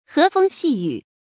注音：ㄏㄜˊ ㄈㄥ ㄒㄧˋ ㄧㄩˇ
和風細雨的讀法